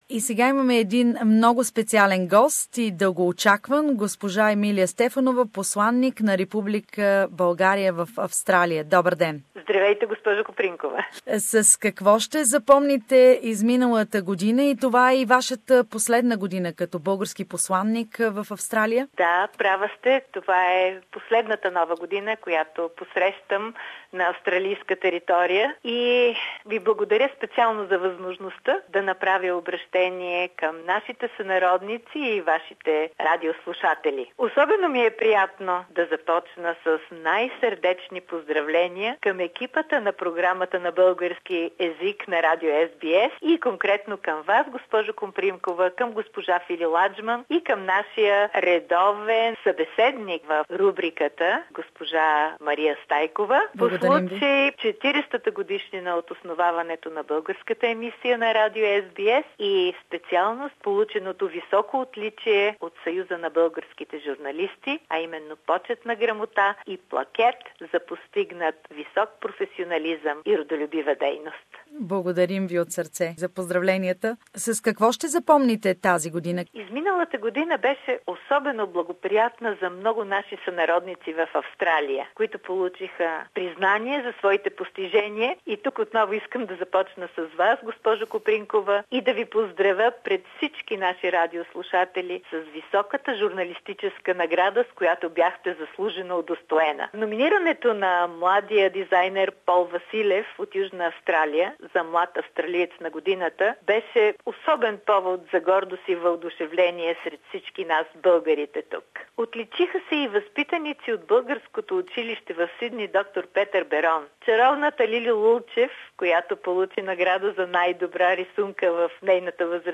Как видя 2017-та година посланика на България в Австралия госпожа Емилия Стефанова. Обръщение към българската общност в Австралия в навечерието на новата 2018-та година.